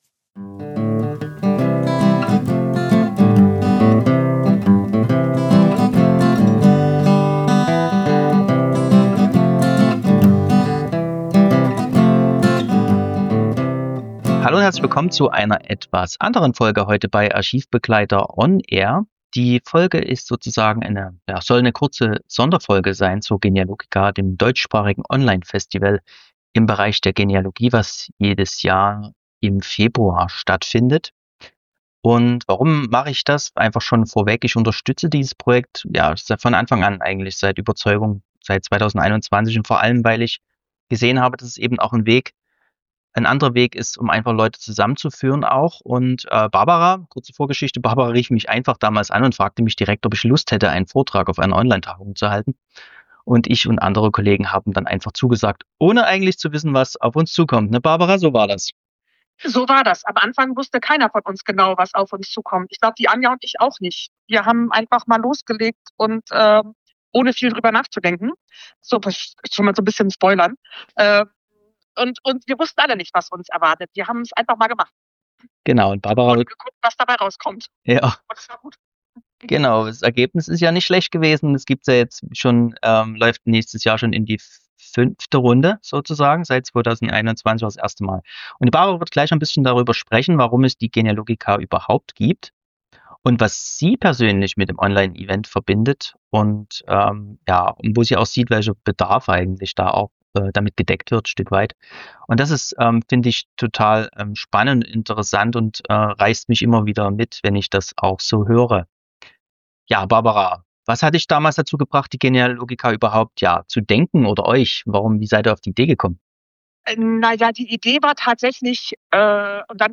– Podcast: Archivbegleiter On Air | Sonderfolge – (Hinweis: Diese Podcastfolge enthält Werbung.